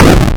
rock_destroyed.wav